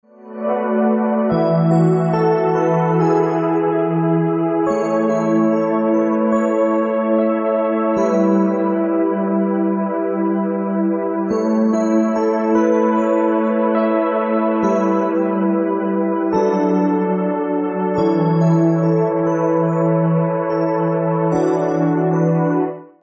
Przepiękna harmonijna muzyka do masażu.